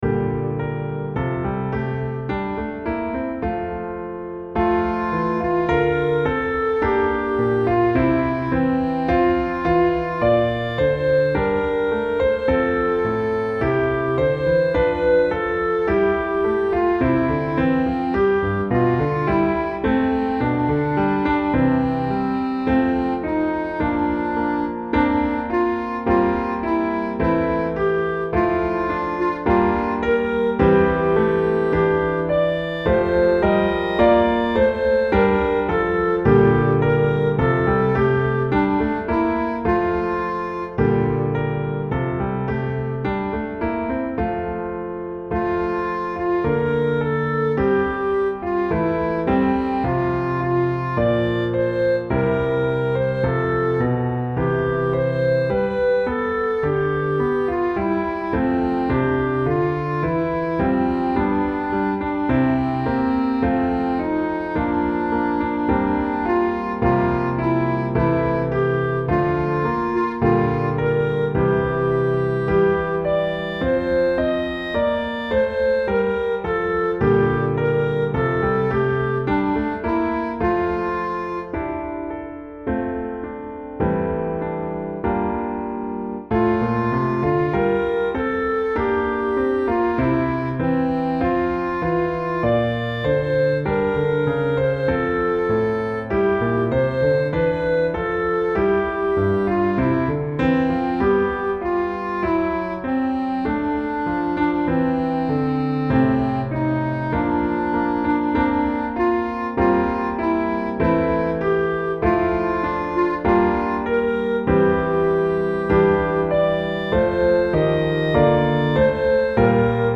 JTAGGsolo.mp3